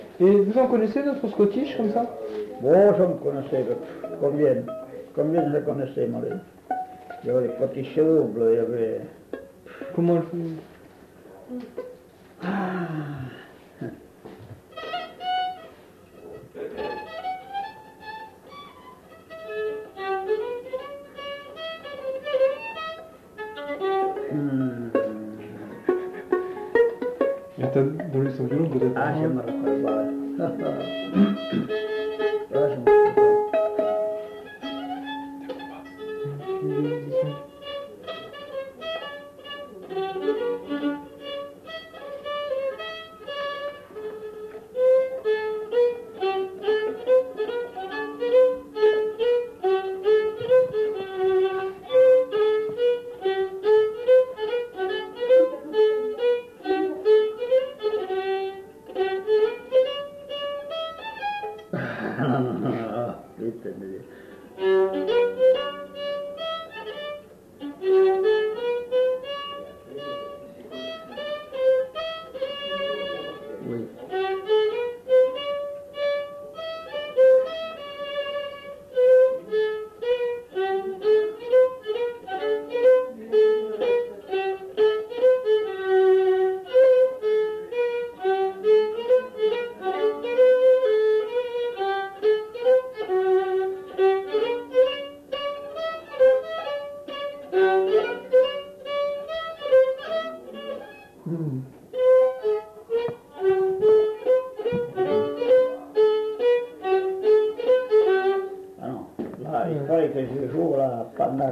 Lieu : Saint-Michel-de-Castelnau
Genre : morceau instrumental
Instrument de musique : violon
Danse : scottish double